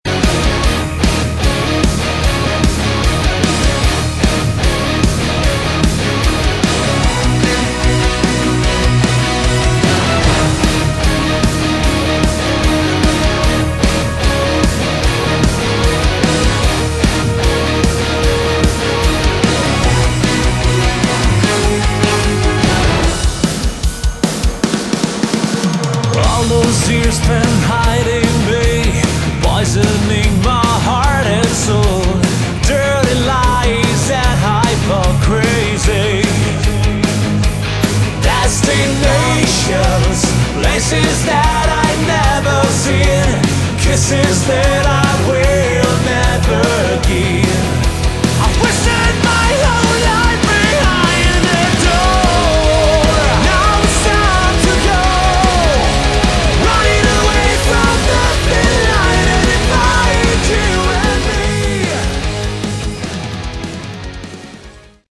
Category: Melodic/Symphonic Metal
guitars, keyboards and orchestral arrangements
lead and backing vocals